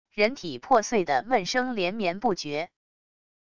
人体破碎的闷声连绵不绝wav音频